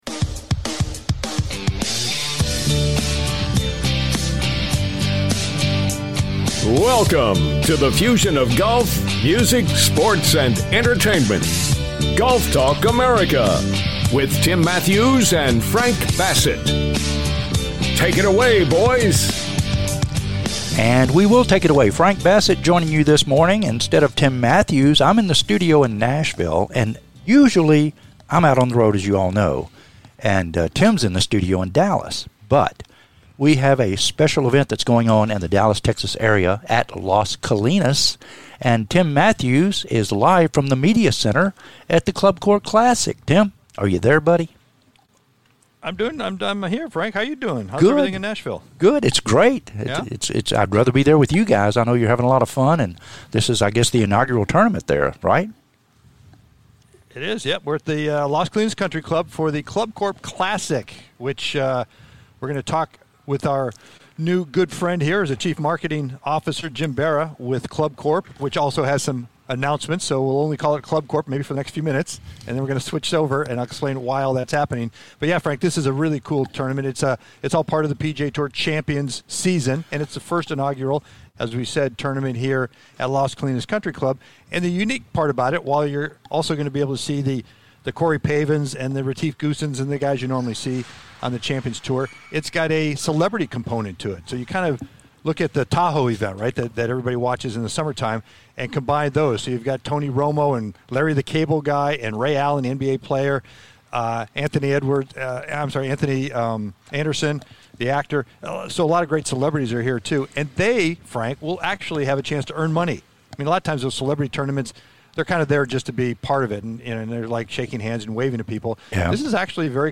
"LIVE" FROM THE CLUBCORP CLASSIC AT LAS COLINAS